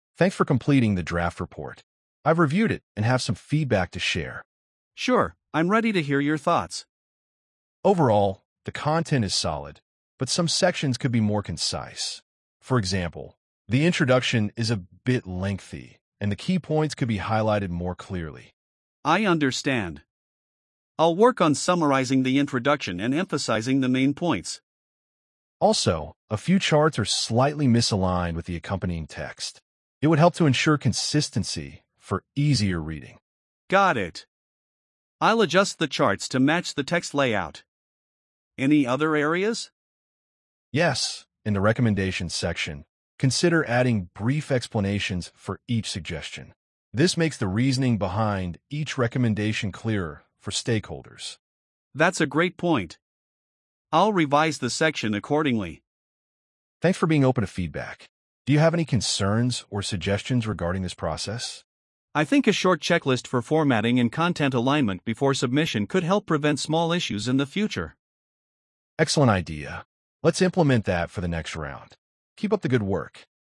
🤝 A manager gives feedback on a draft report.